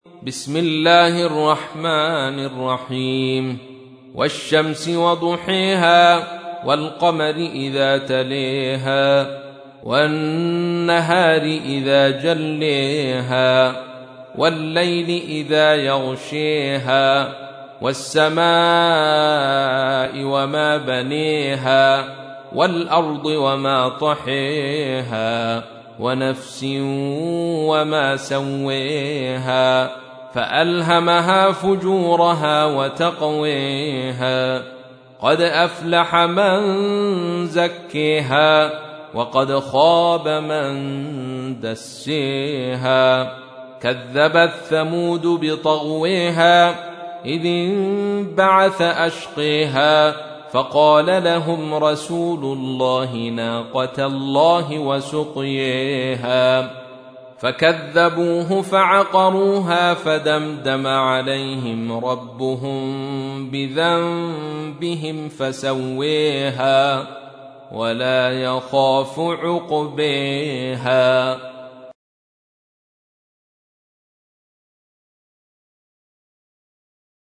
تحميل : 91. سورة الشمس / القارئ عبد الرشيد صوفي / القرآن الكريم / موقع يا حسين